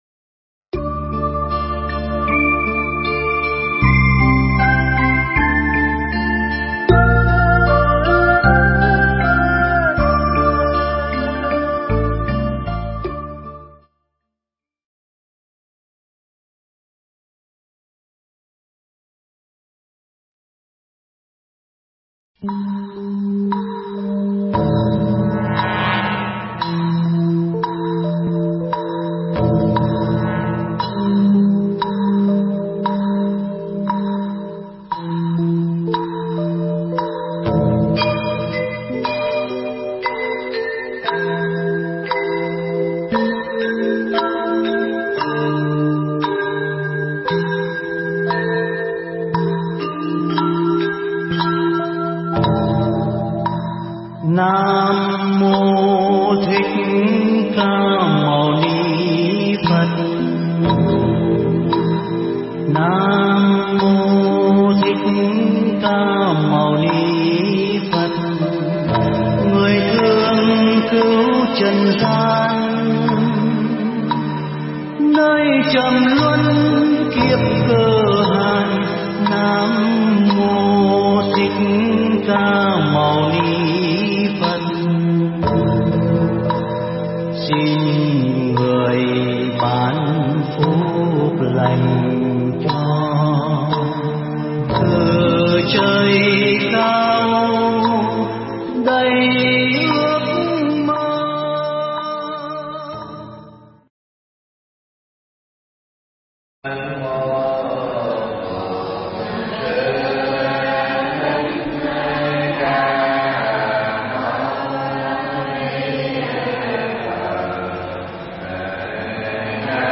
Mp3 Thuyết pháp Khai Tâm